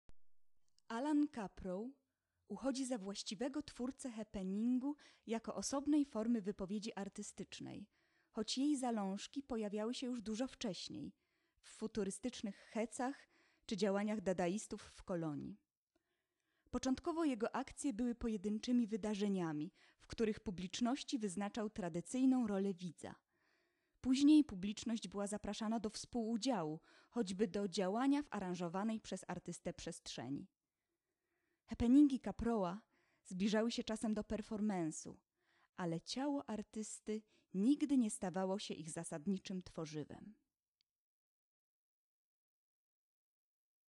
lektor001.wma